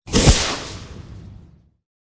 • 声道 單聲道 (1ch)